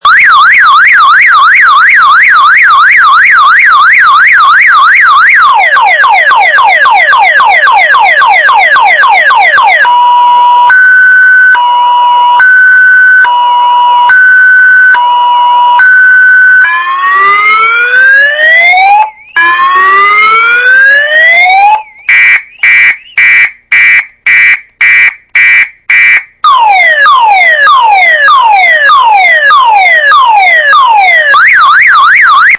6トーンサイレン　チャープ音　警告
フルサイレン
2WAY　LCDリモートアンサーバック　警告&フルサイレン